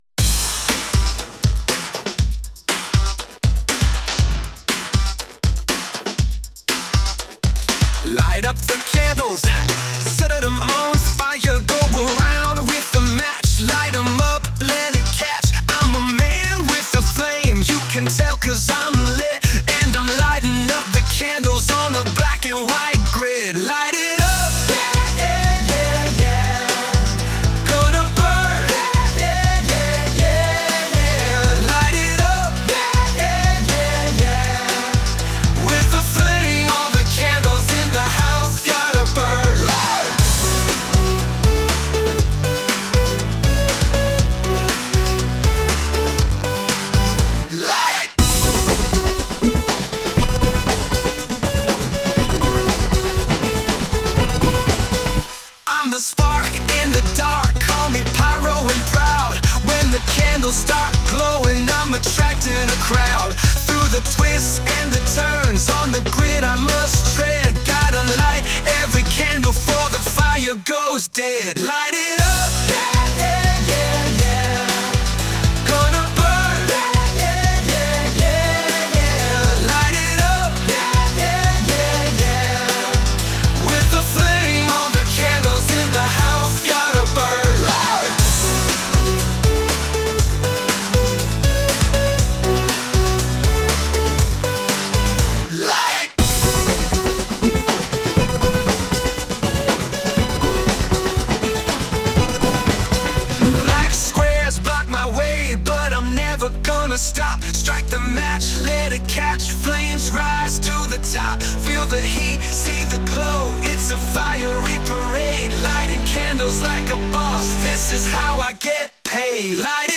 90s Pop version
Sung by Suno